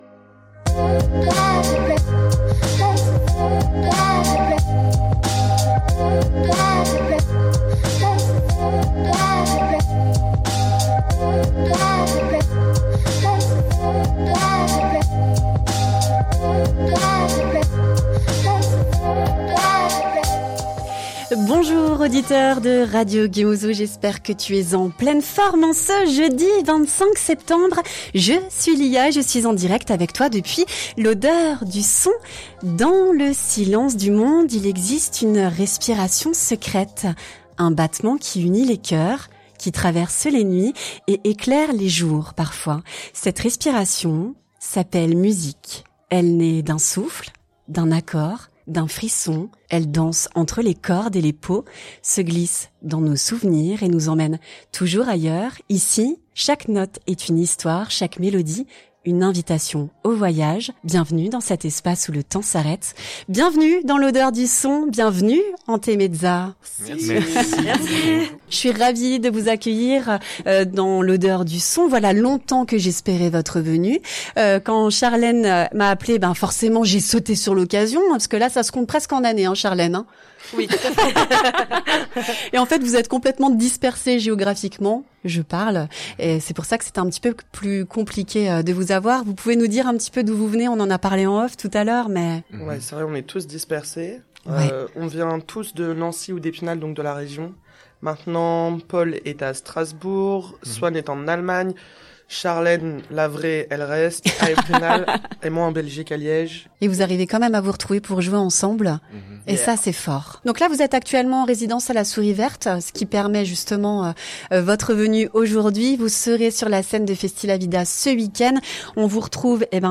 Un groupe de "Cosmic rock" qui compose leurs morceaux autour de sensations ...